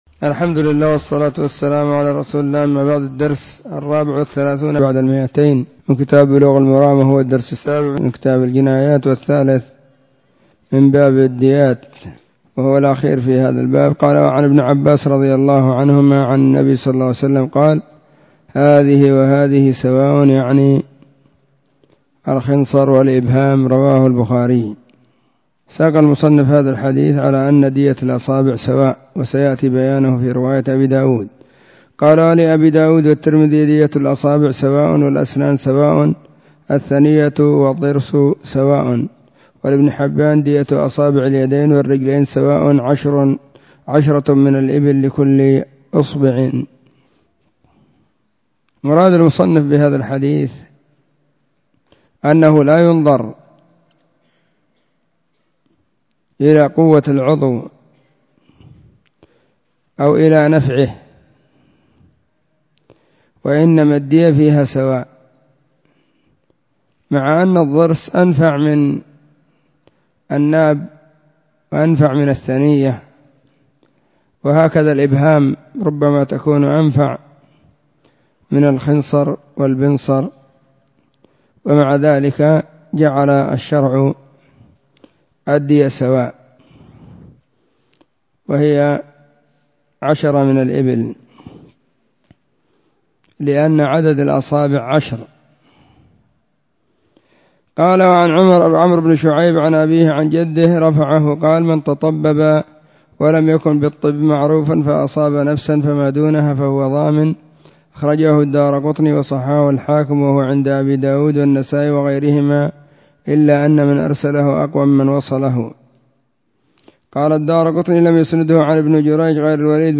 📢 مسجد الصحابة – بالغيضة – المهرة، اليمن حرسها الله.